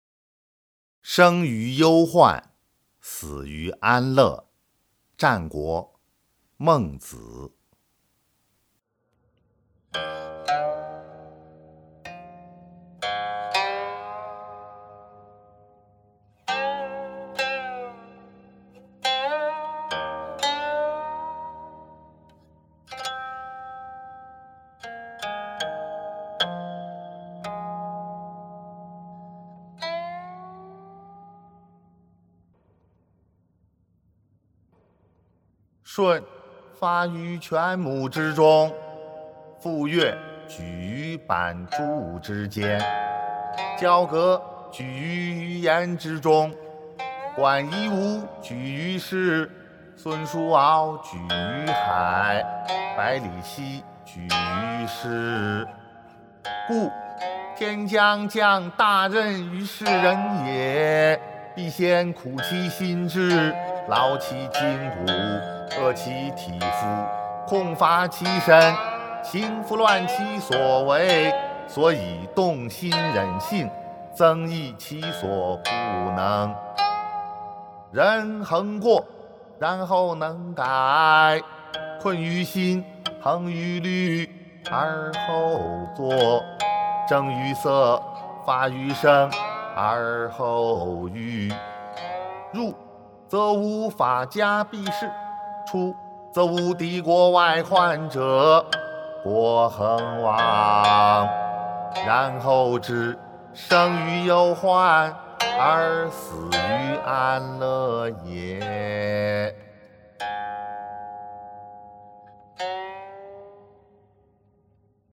《孟子》生于忧患，死于安乐（吟咏）